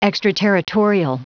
Prononciation du mot extraterritorial en anglais (fichier audio)
extraterritorial.wav